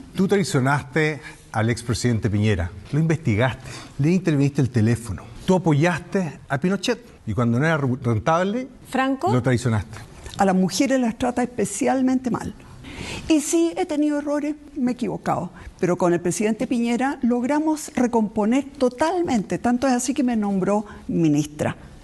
Los dardos cruzados que dejó primer debate presidencial televisivo